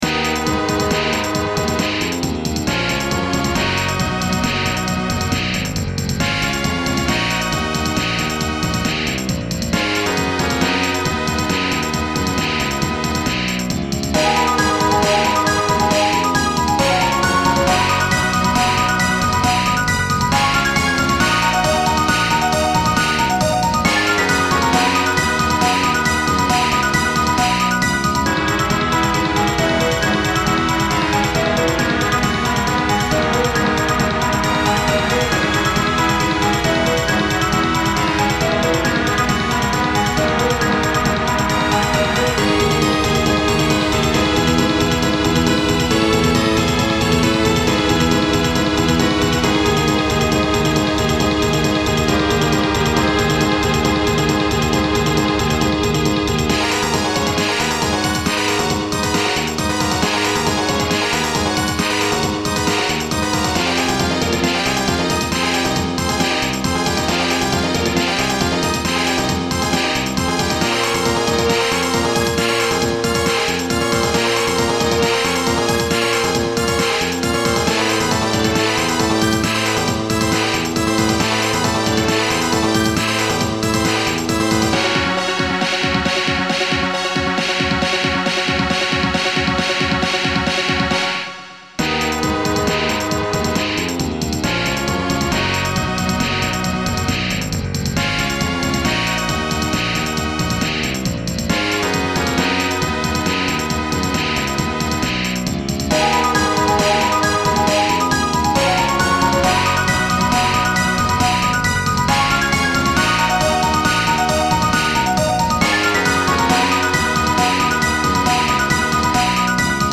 chiptune
epic